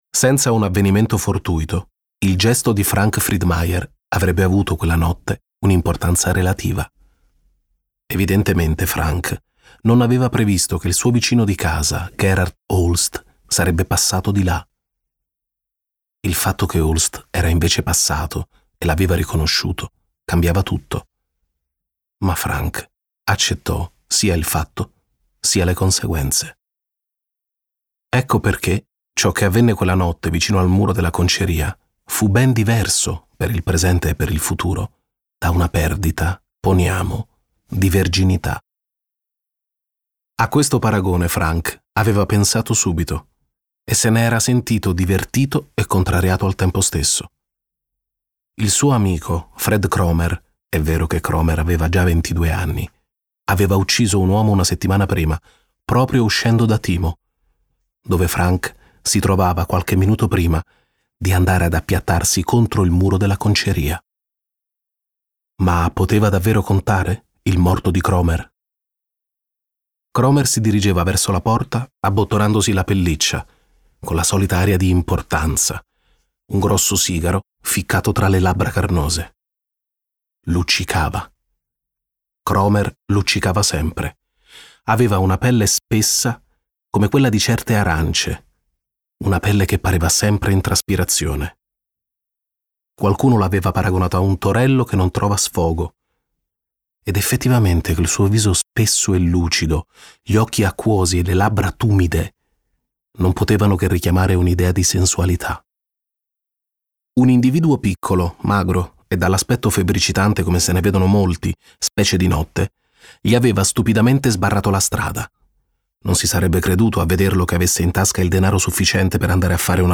letto da Marco D'Amore
Versione audiolibro integrale